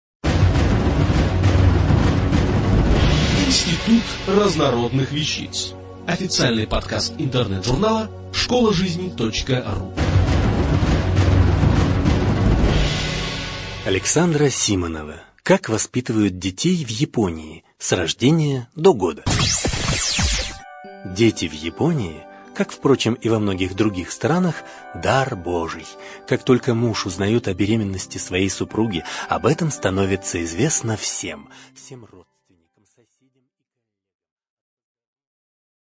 Аудиокнига Как воспитывают детей в Японии? С рождения до года | Библиотека аудиокниг